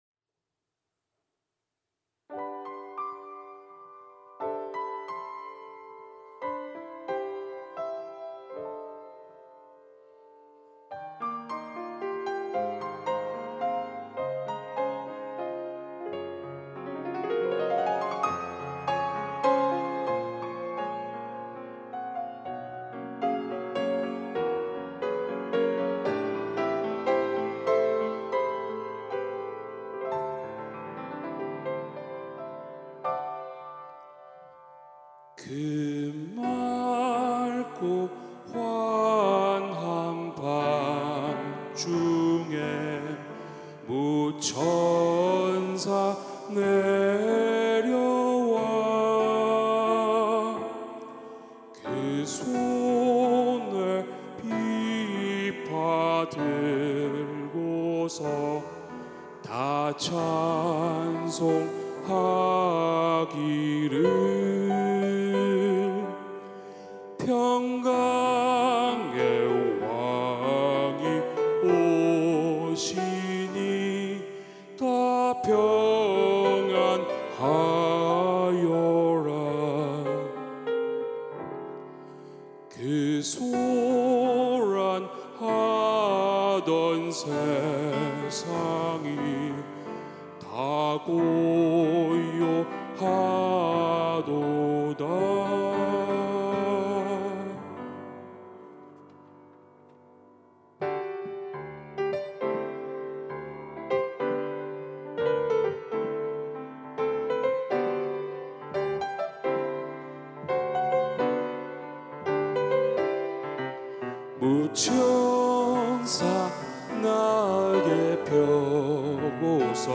특송과 특주 - 그 맑고 환한 밤중에